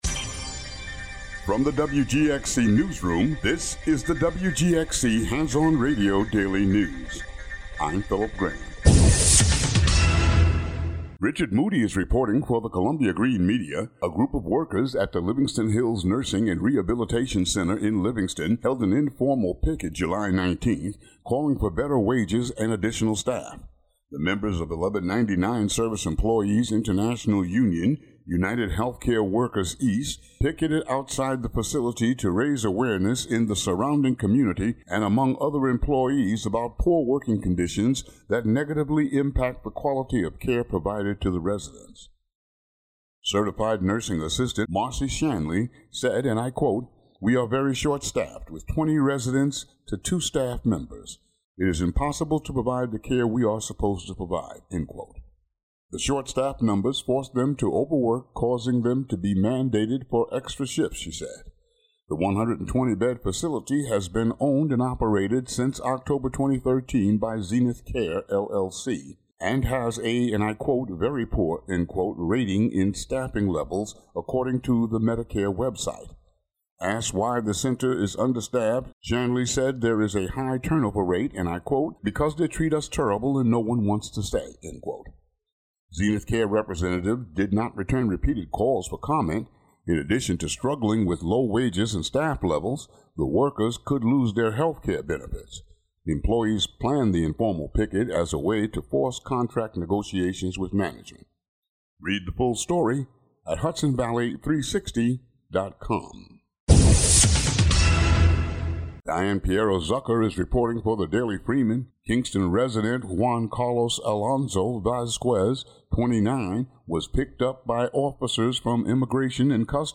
"All Together Now!" is a daily news show brought t...